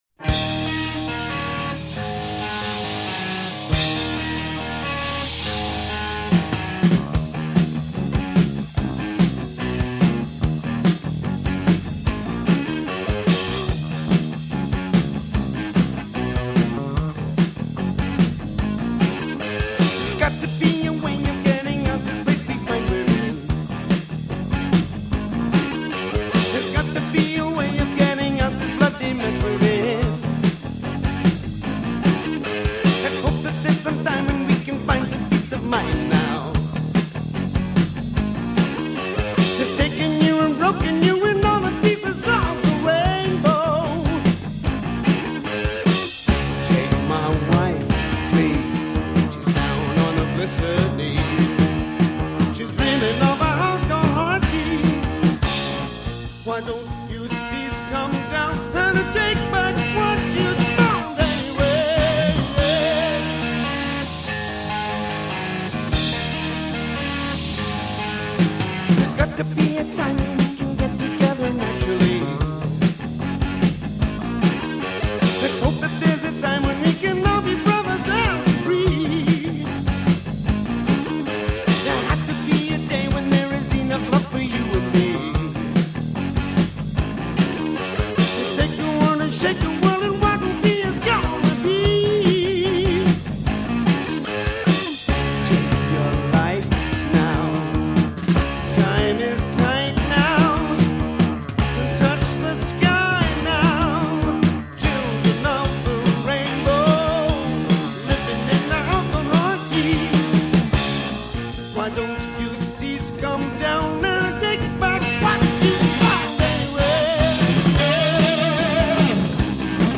power trio format
the bass is high in the mix